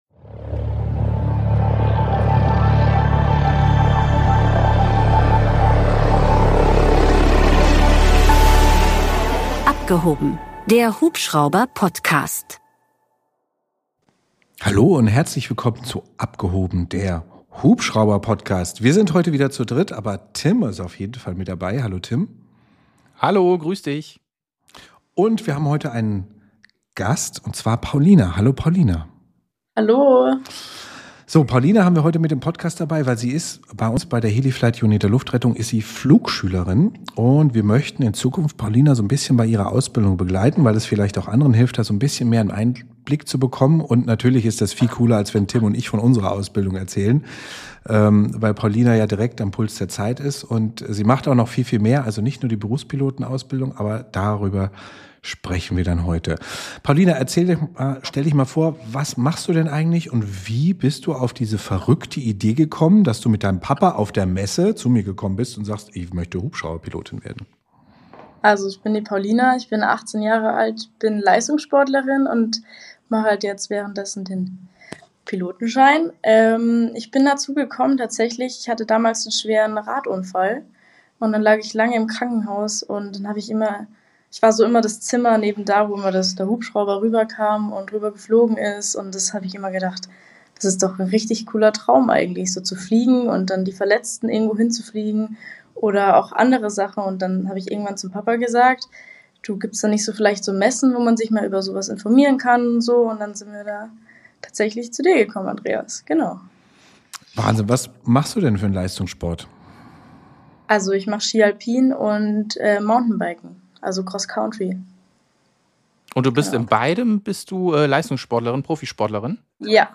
Zwei totale Hubschrauber- und Luftfahrtenthusiasten sprechen über alles, was mit der Fliegerei zu tun hat.